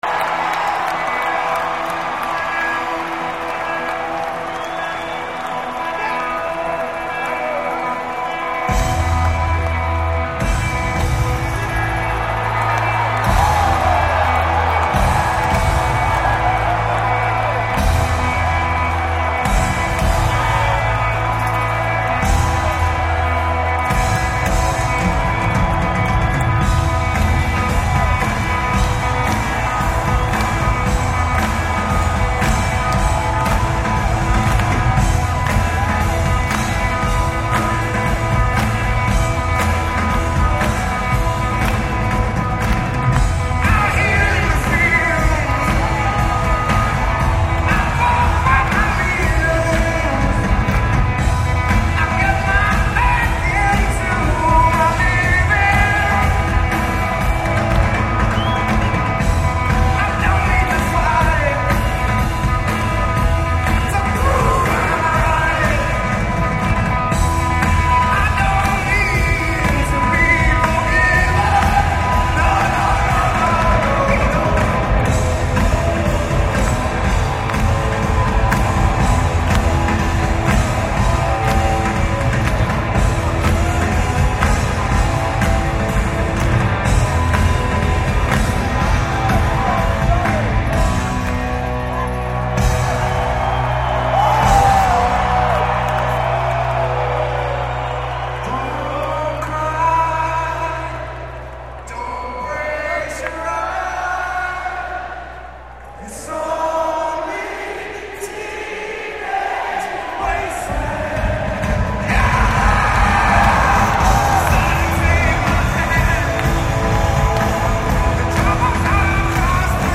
live in Chicago 6/29/98